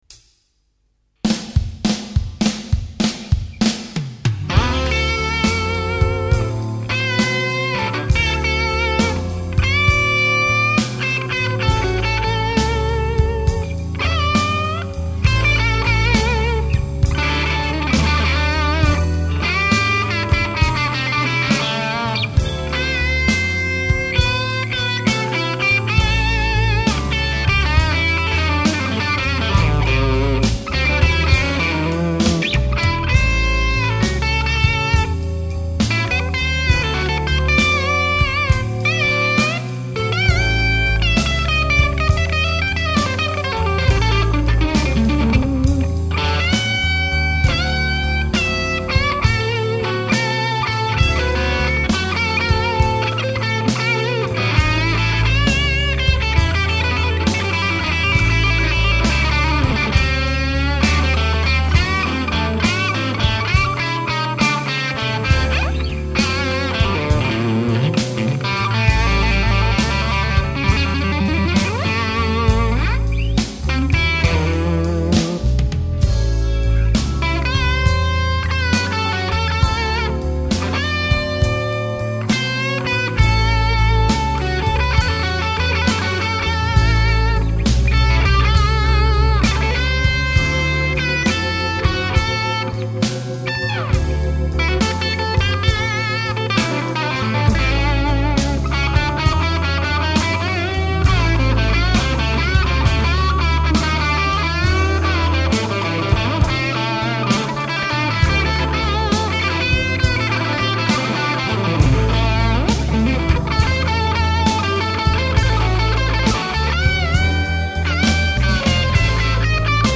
������� � ���� Fender Stratocaster ������ �������� 70-�